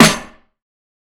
TC2 Snare 21.wav